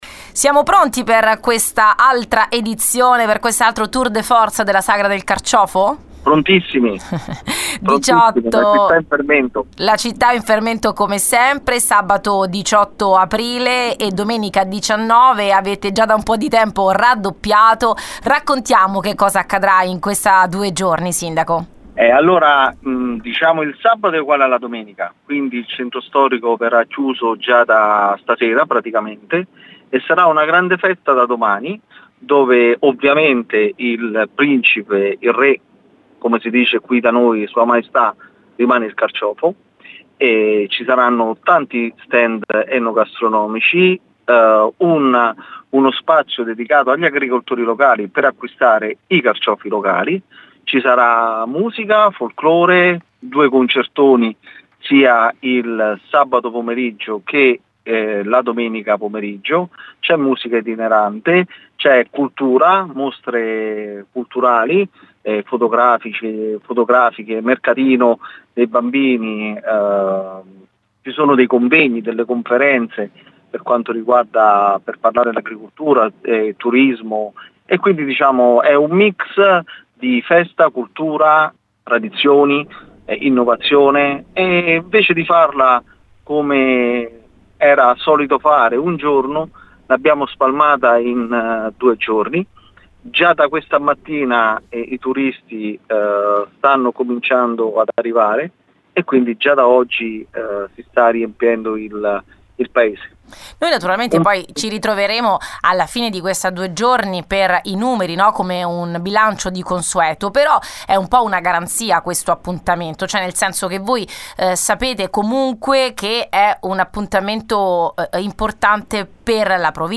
Sezze pronta per la Sagra del Carciofo: l’intervista al Sindaco Lucidi